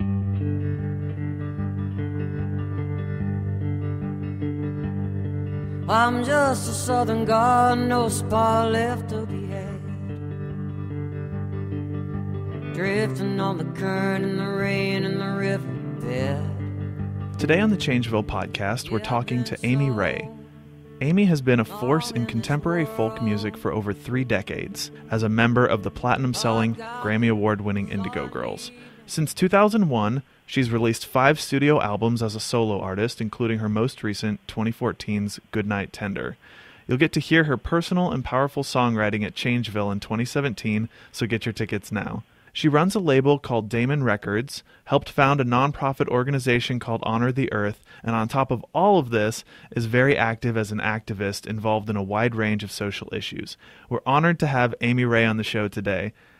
(audio capture from youtube)